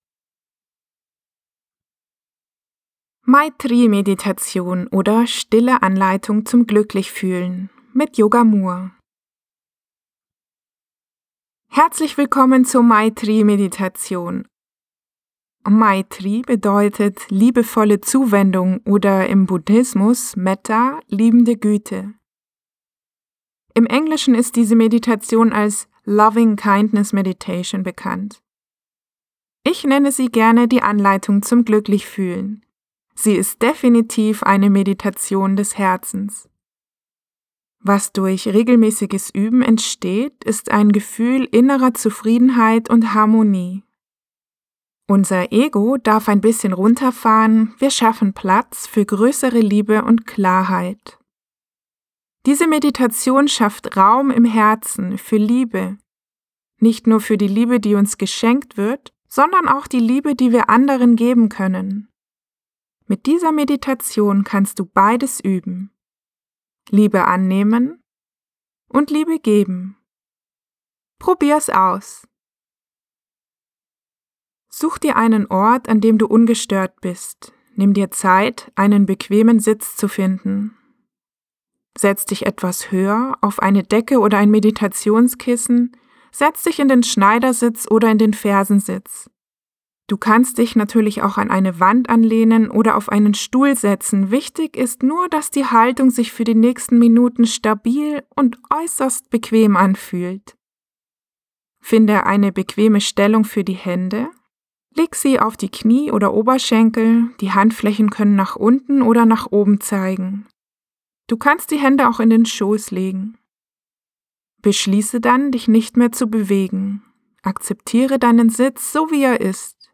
Meditation
Mit dieser Meditation kannst du beides üben: Liebe annehmen und Liebe geben. Einfach bequem hinsetzen, Augen schließen und meiner Stimme lauschen…